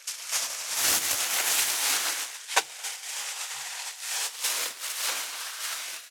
620コンビニ袋,ゴミ袋,スーパーの袋,袋,買い出しの音,ゴミ出しの音,袋を運ぶ音,
効果音